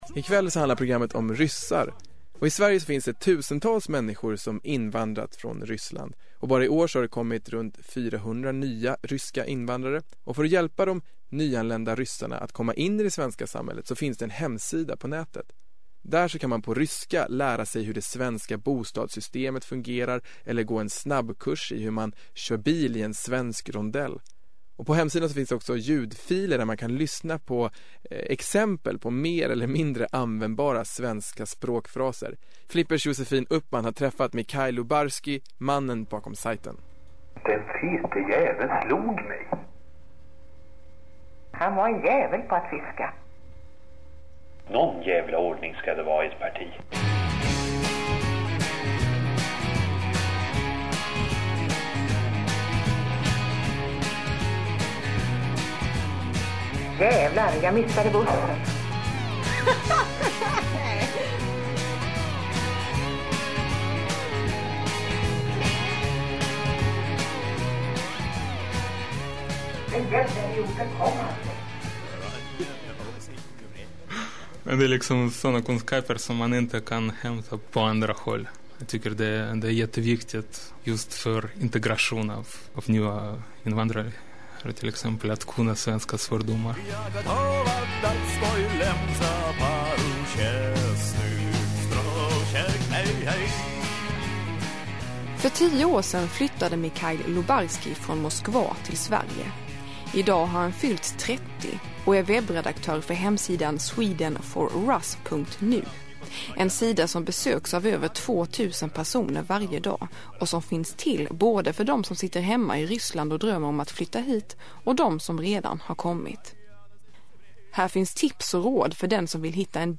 Аудиоинтервью